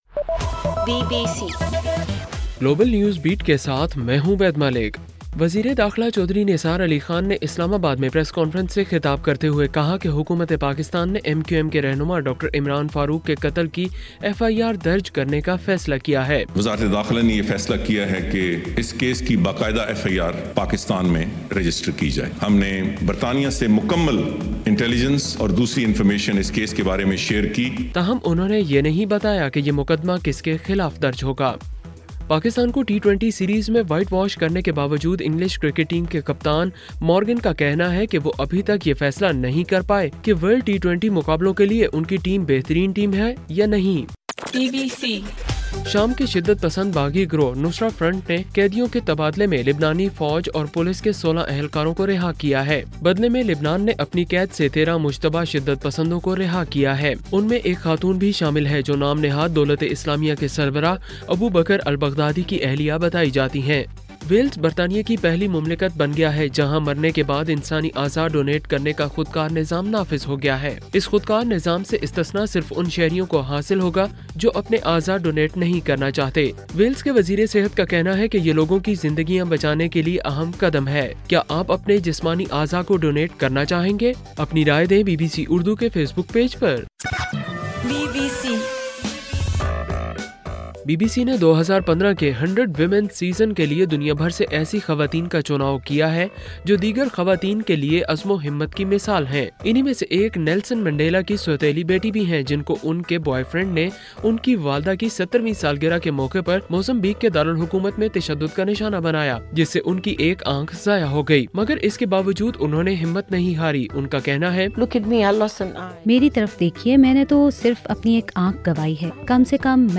دسمبر 1: رات 9 بجے کا گلوبل نیوز بیٹ بُلیٹن